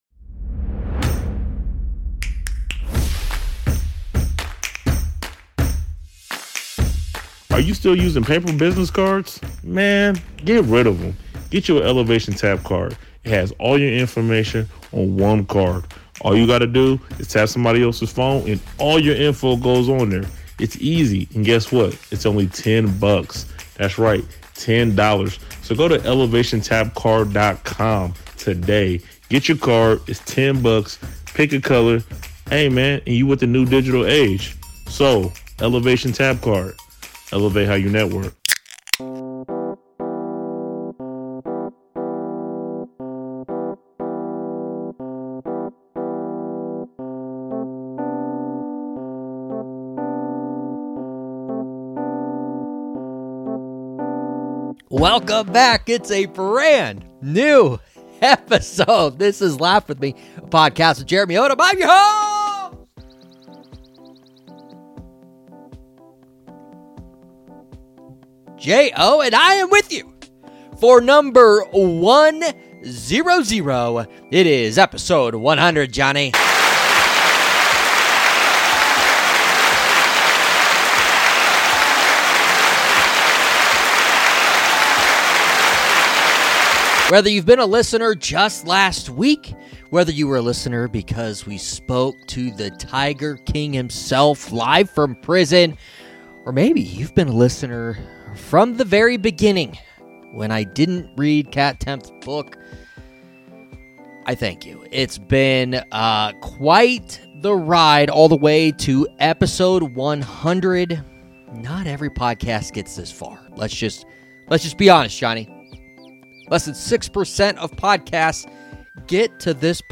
Also, some of our favorite guests over the past 100 episodes call in to help celebrate the momentous milestone!